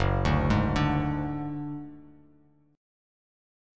F#sus2#5 chord